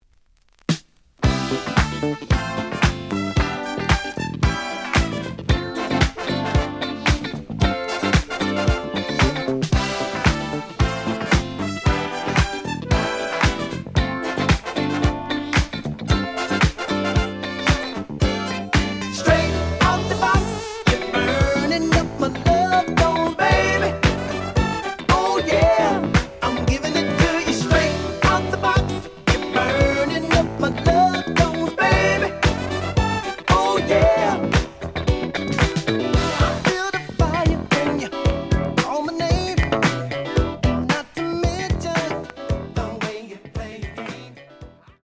試聴は実際のレコードから録音しています。
●Genre: Disco
●Record Grading: VG+~EX- (盤に若干の歪み。多少の傷はあるが、おおむね良好。)